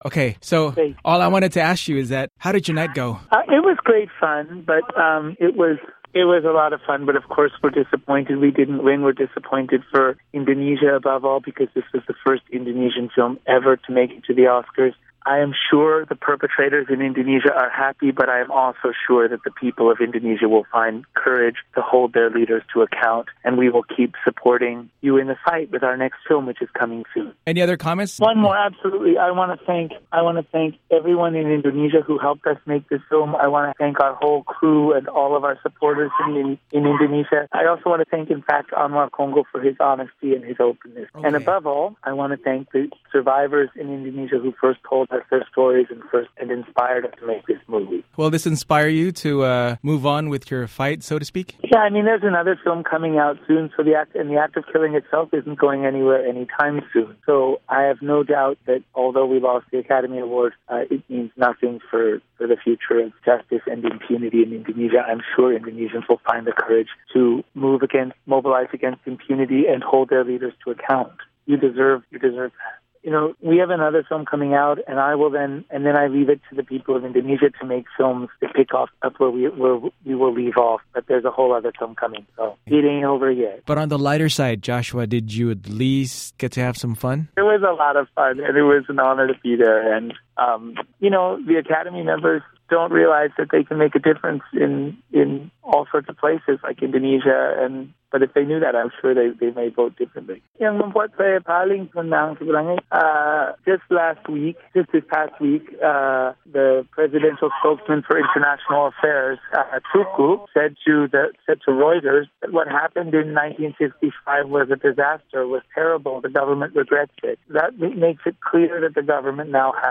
Wawancara VOA dengan Joshua Oppenheimer Pasca Oscars 2014